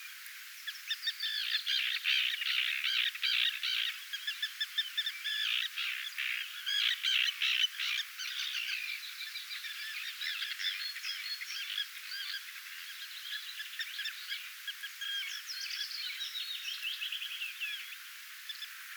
lapintiiran ääntelyä